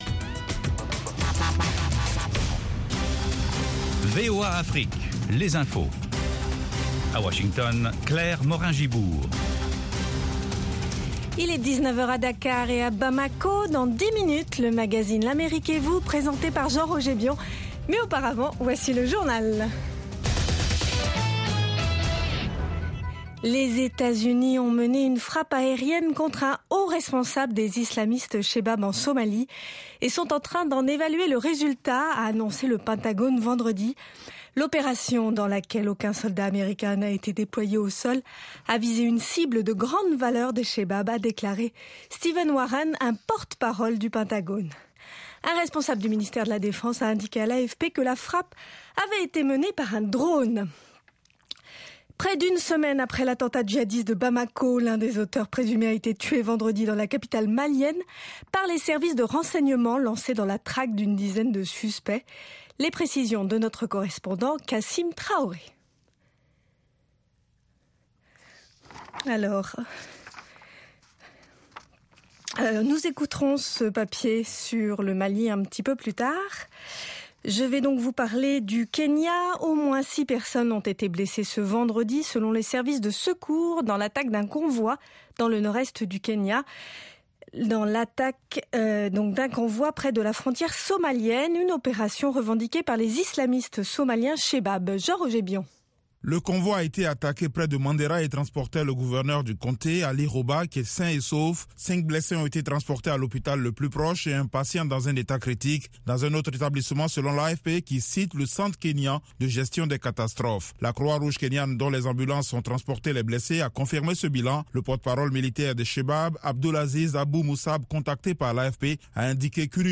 Newscast